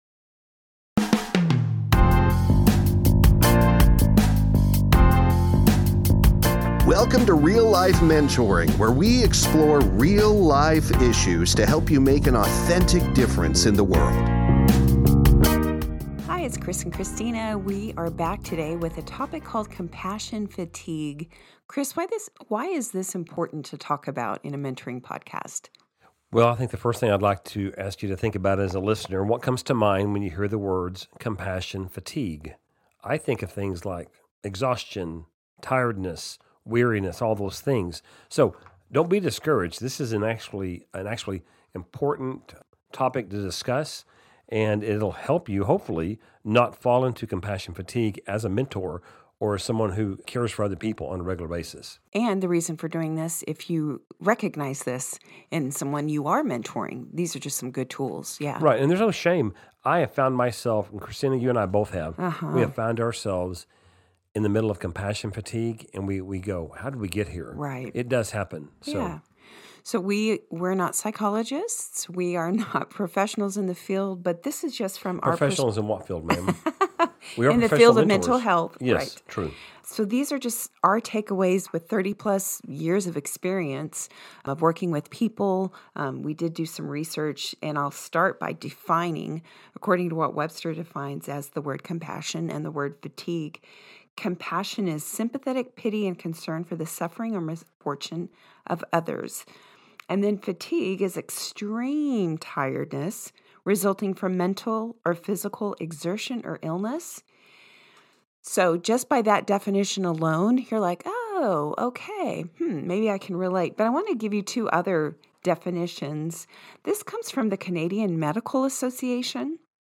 No script!